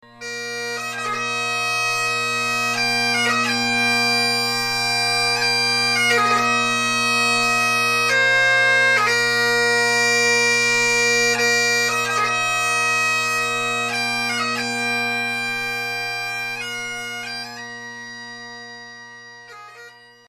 memphis tennessee bagpipes funeral members military detail piper funerals bagpipers
Perhaps it is the ancient scale of the instrument, or the soulful, haunting sounds.
Bagpipes have a narrow range of nine notes without accidentals and a peculiar scale.